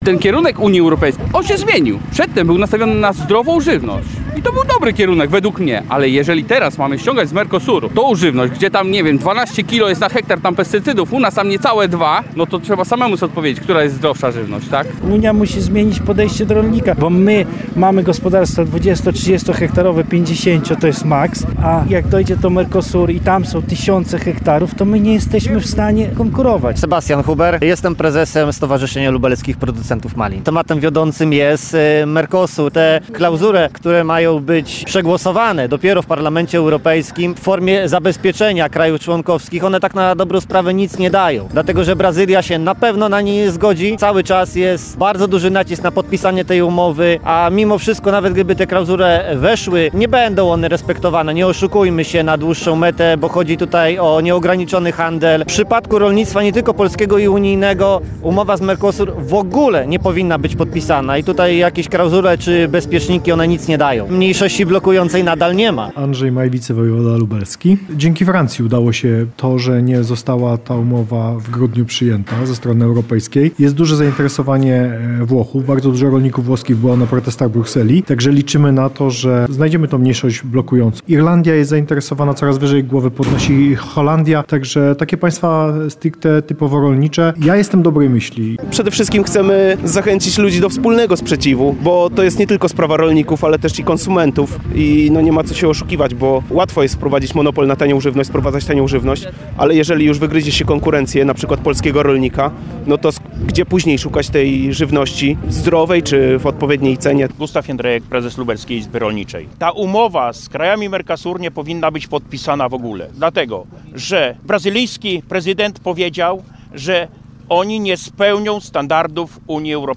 Podobnie było w Lublinie, gdzie manifestujący zebrali się na placu Zamkowym, by pokazać swój sprzeciw i obawy. Te dotyczyły głównie wątpliwości co do używania nadmiernej ilości pestycydów w rolnictwie krajów Ameryki Południowej. Rolnicy żądają zablokowania umowy.
Protest-przeciw-umowie-z-Mercosur-w-Lublinie.mp3